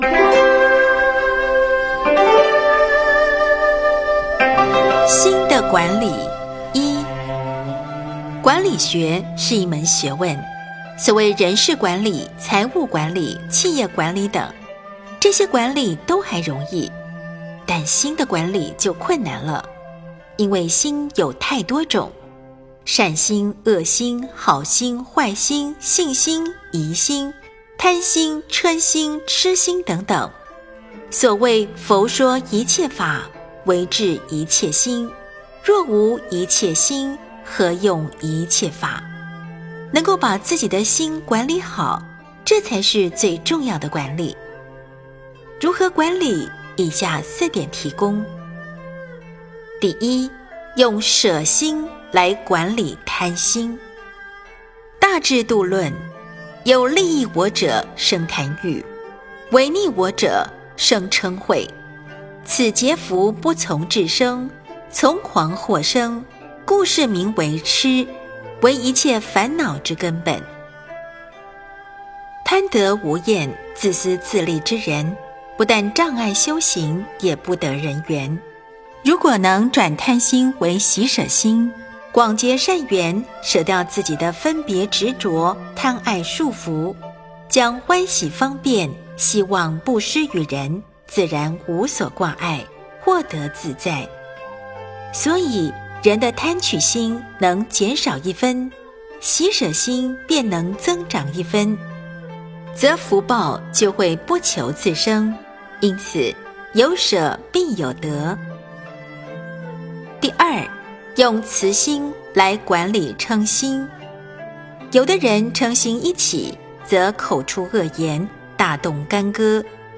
38.心的管理(一)--佚名 冥想 38.心的管理(一)--佚名 点我： 标签: 佛音 冥想 佛教音乐 返回列表 上一篇： 36.禅的修行--佚名 下一篇： 42.驭心--佚名 相关文章 缘悲经--竹清仁波切 缘悲经--竹清仁波切...